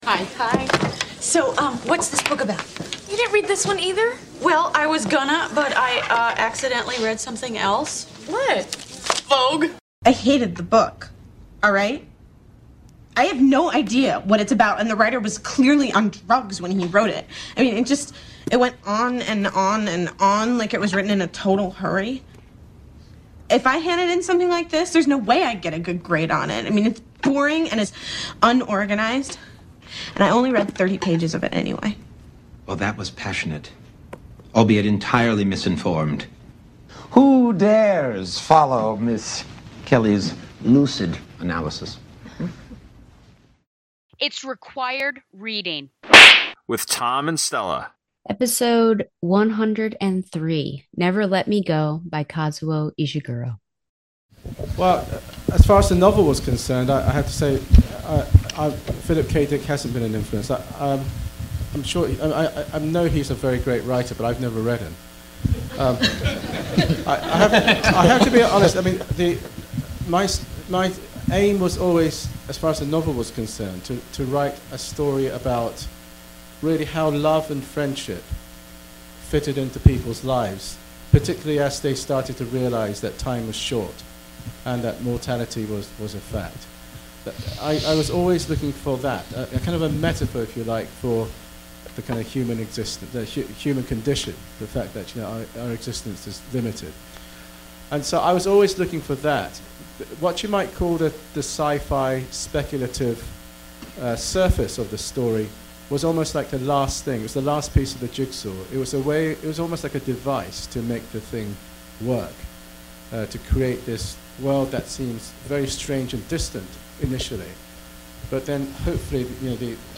is two teachers talking about literature.